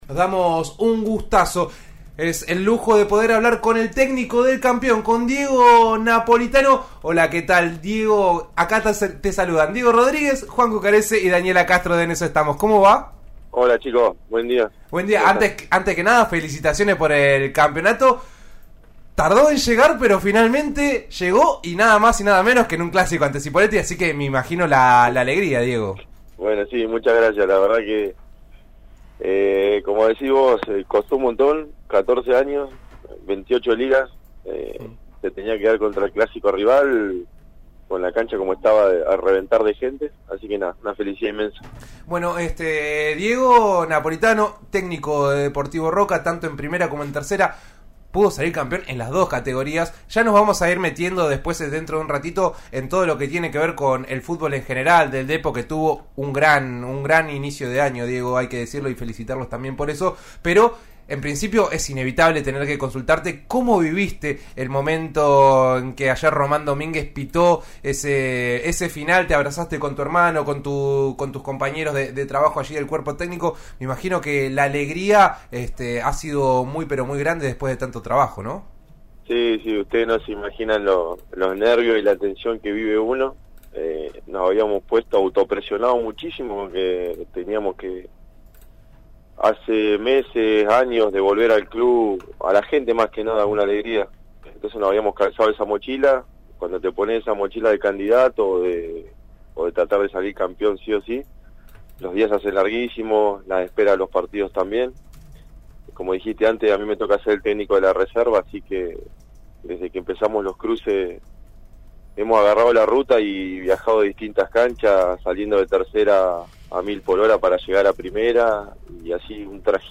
Apenas terminado el partido de vuelta contra Cipolletti, y en medio de los festejos, ambos dialogaron con Río Negro y dejaron sus sensaciones.